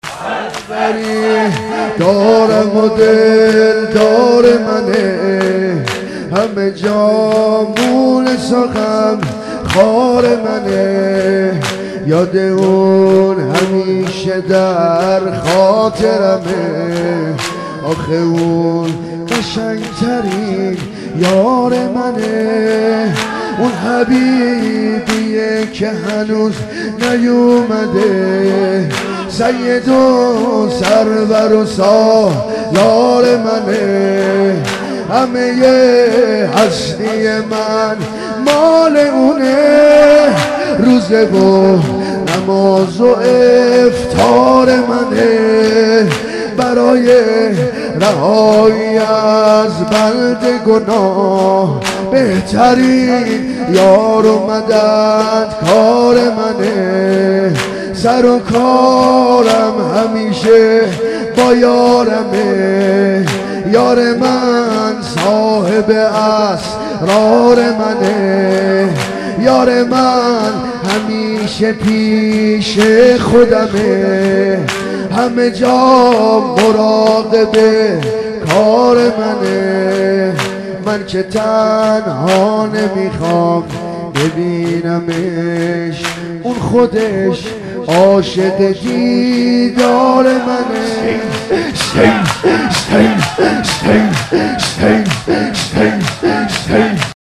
دلبری دارم و دلدار منه | شور | حاج عبدالرضا هلالی